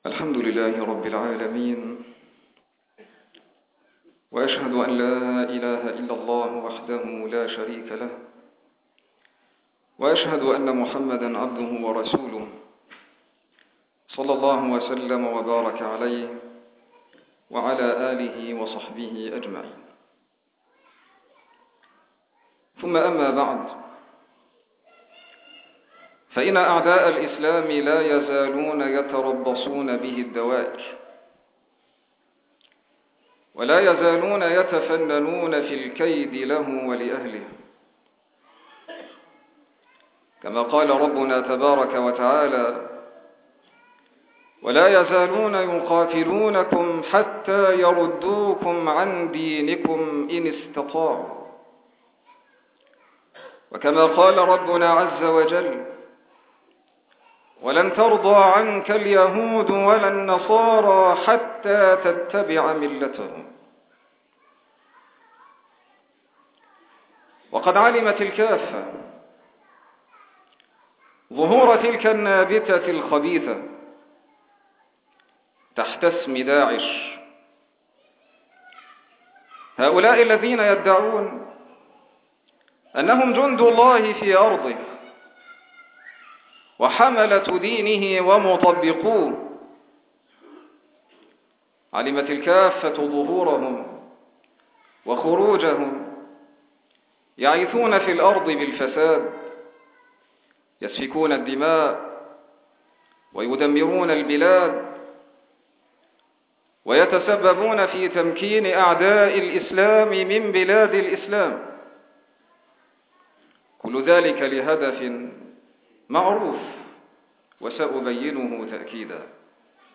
من خطبة الجمعة